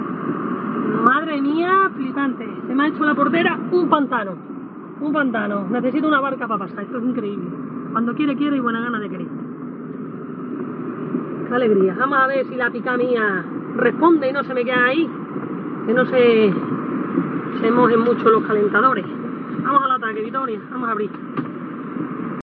Sonido de vecinos durante el temporal